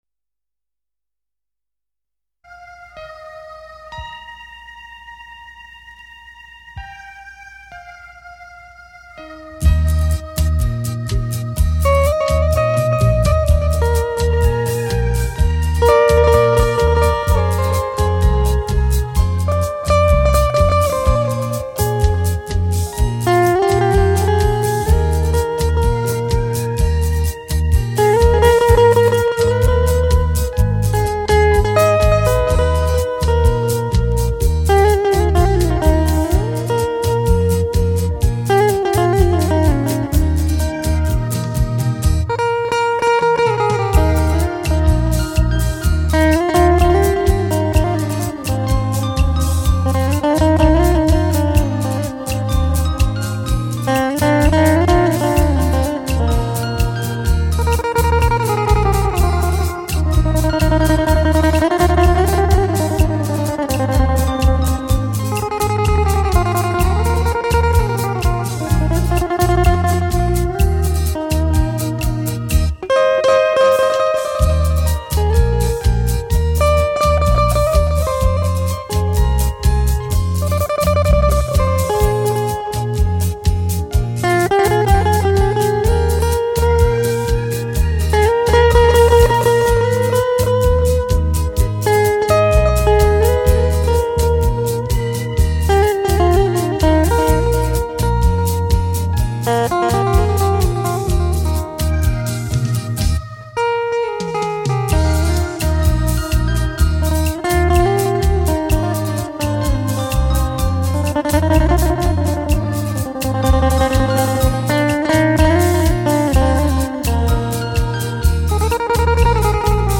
* Ca sĩ: Không lời